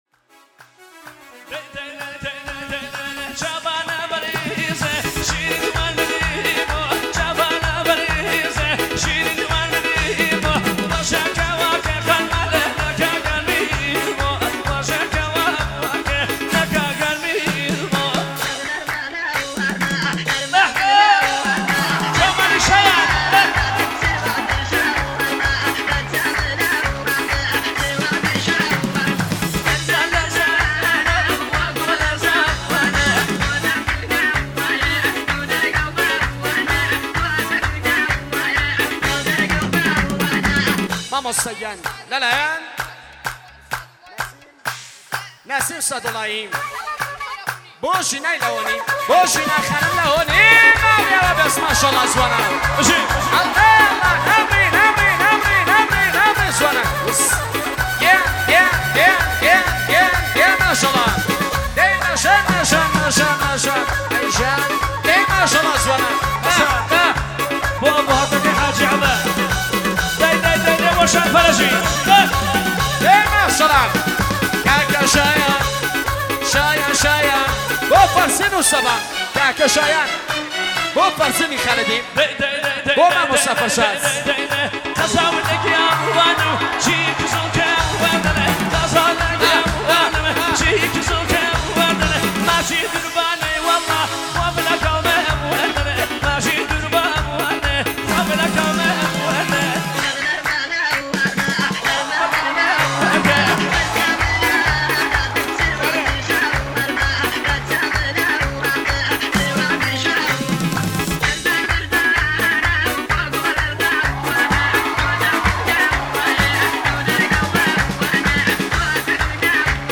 اهنگ کردی